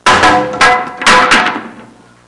Falling Wood Sound Effect
Download a high-quality falling wood sound effect.
falling-wood.mp3